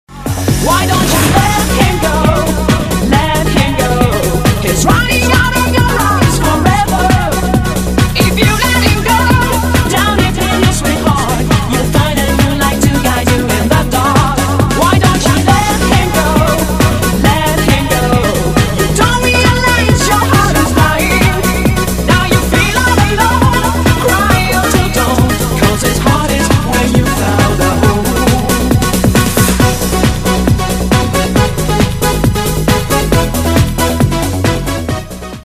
分类: MP3铃声